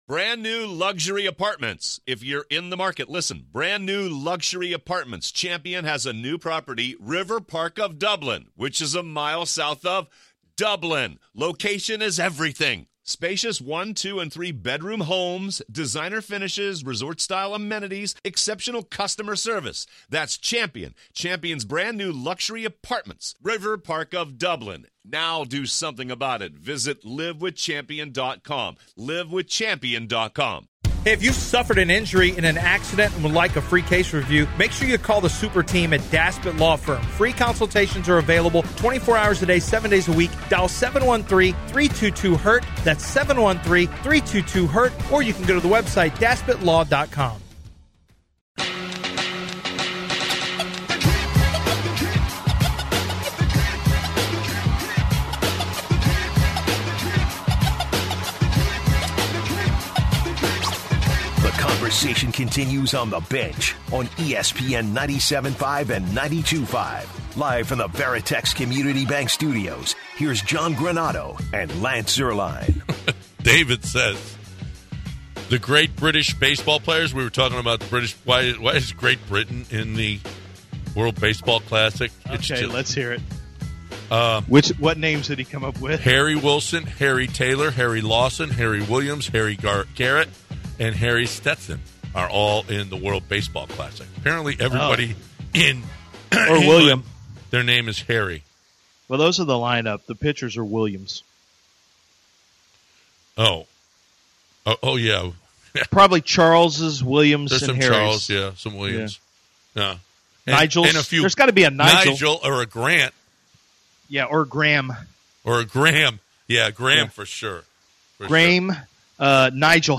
Also, we hear from callers who would give their feelings on the NCAA Tournament Bracket. Do you feel Texas A&M was properly ranked?